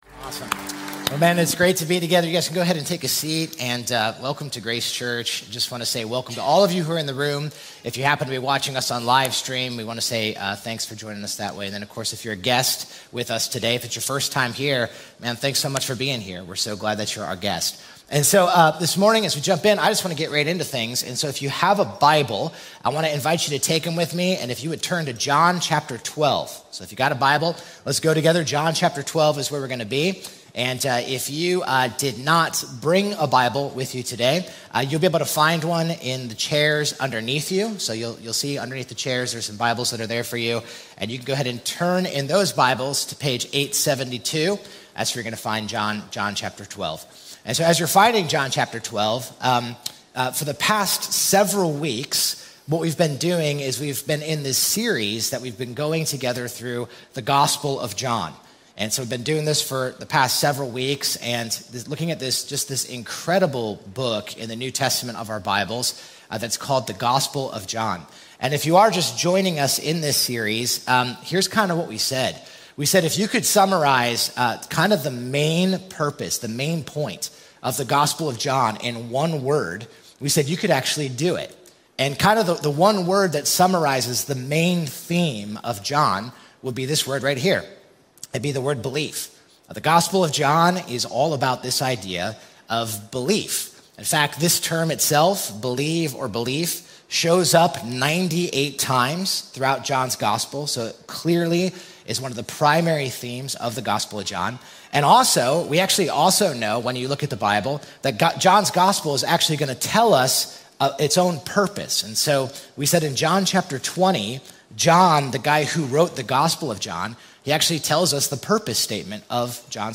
Deepening_of_Belief_Worship.mp3